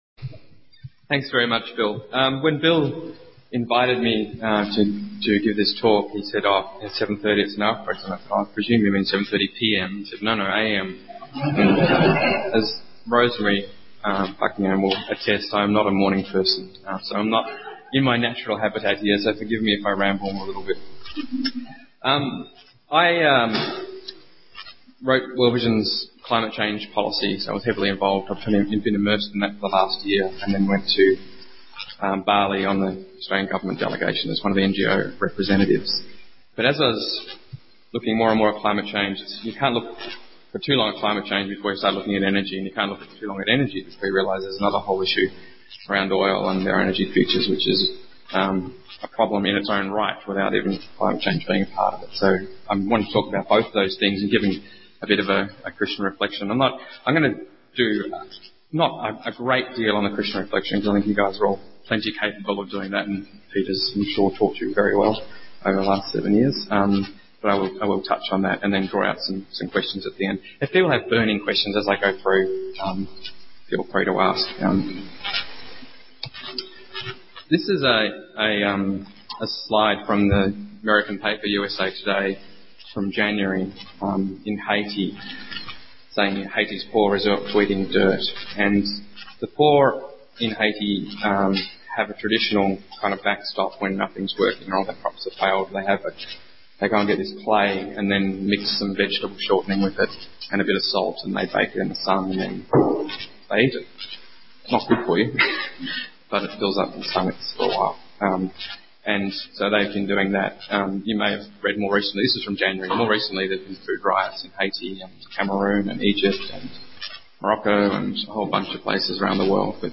Please note that the quality of this recording is of a lower standard.